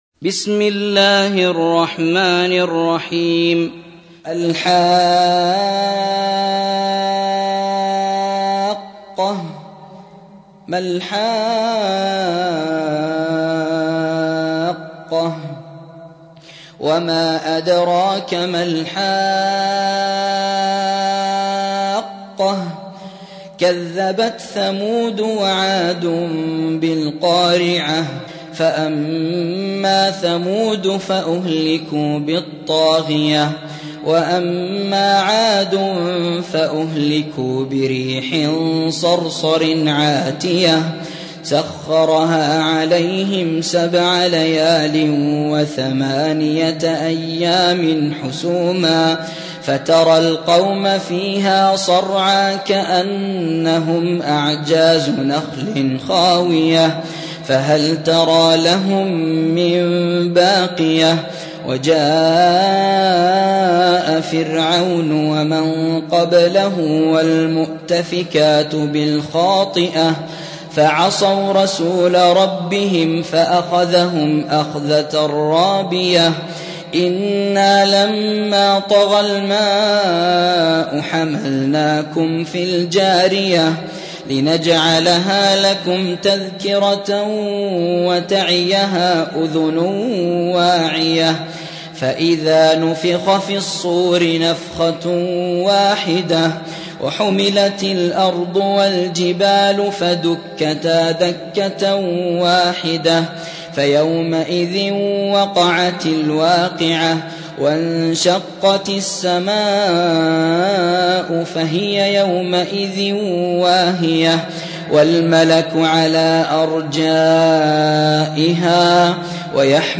69. سورة الحاقة / القارئ